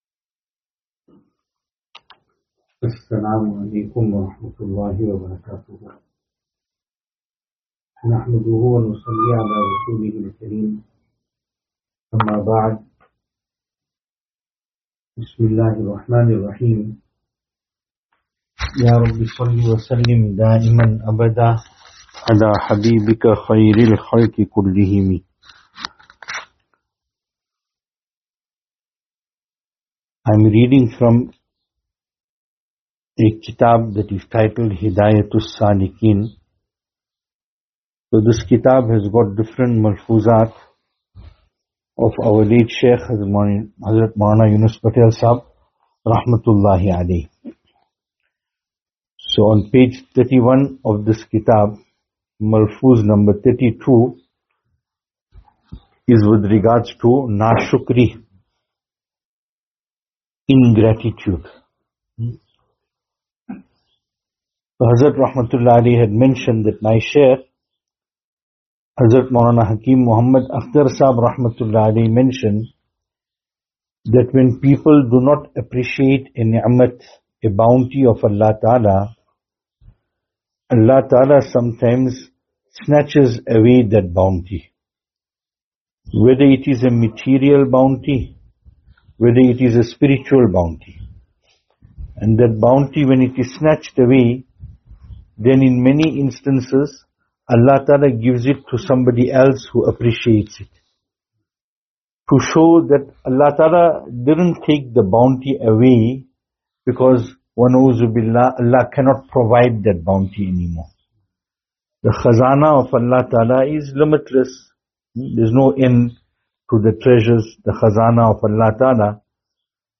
Ladies Programme, Benoni